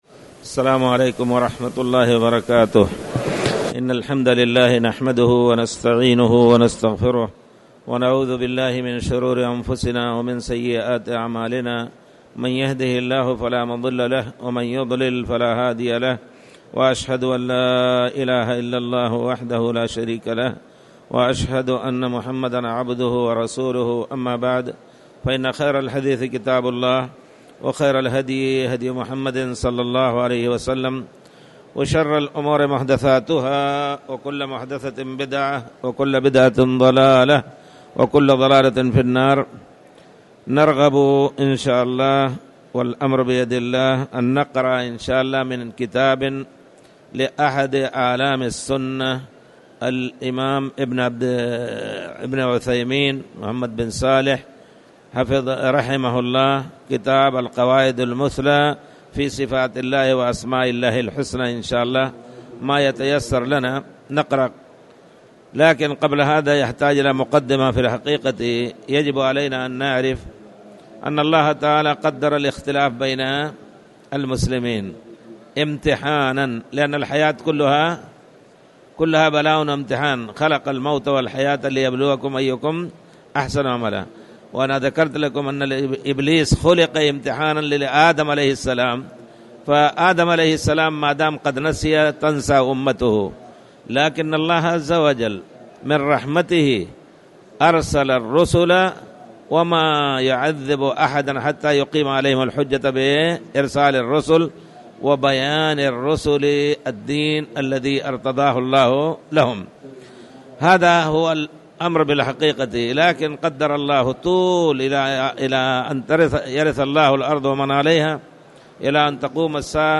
تاريخ النشر ٢٣ رمضان ١٤٣٨ هـ المكان: المسجد الحرام الشيخ